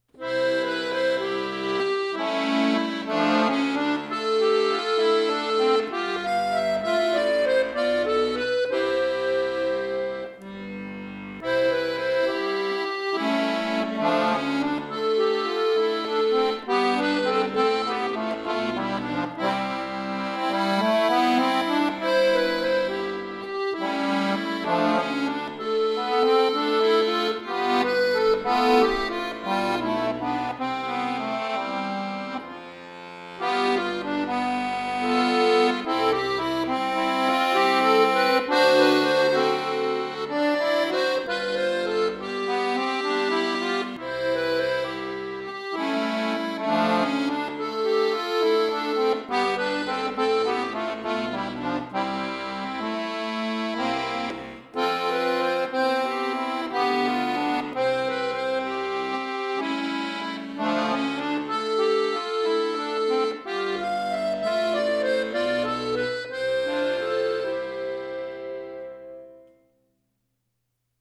Gefühlvoller Folksong
neu arrangiert für Akkordeon solo
Folk, Slow Waltz